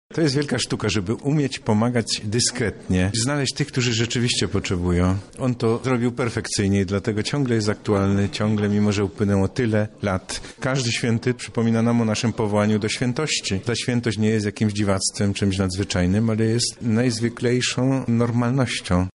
O postaci świętego z Myry mówi metropolita lubelski, abp Stanisław Budzik